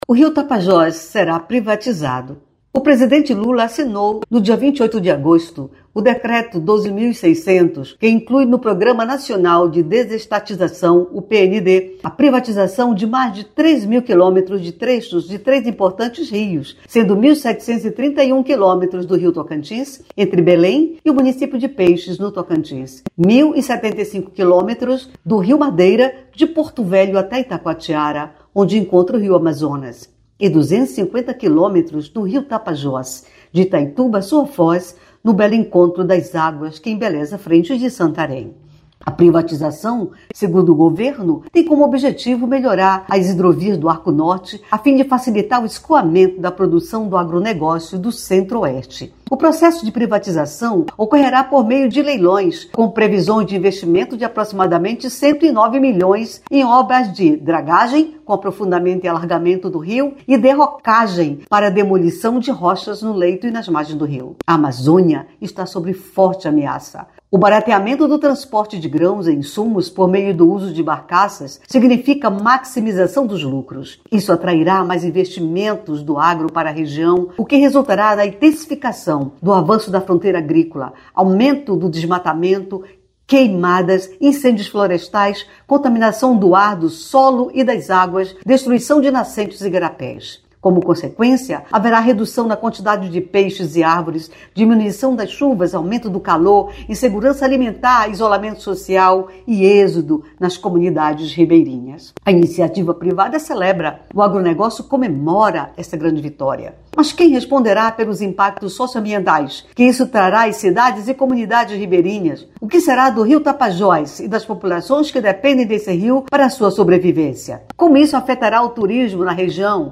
EDITORIAL-1.mp3